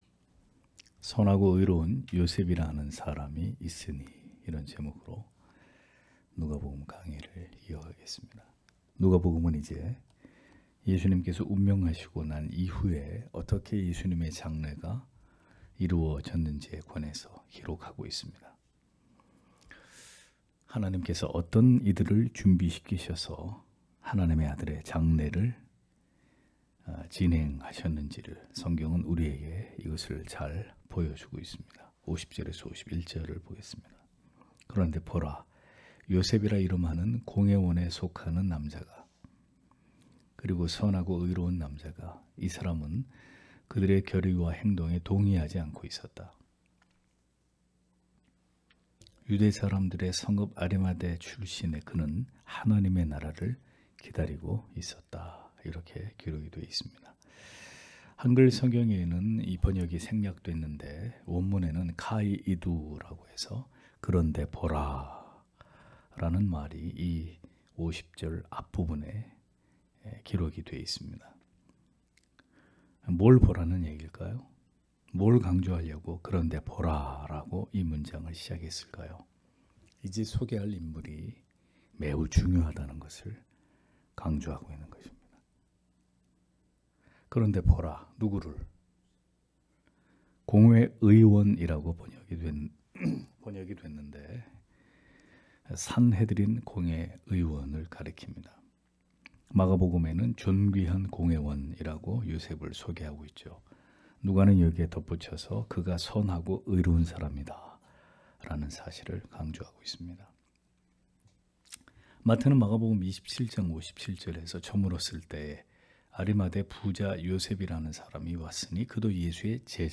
금요기도회 - [누가복음 강해 181] '선하고 의로운 요셉이라는 사람이 있으니' (눅 23장 50- 56절)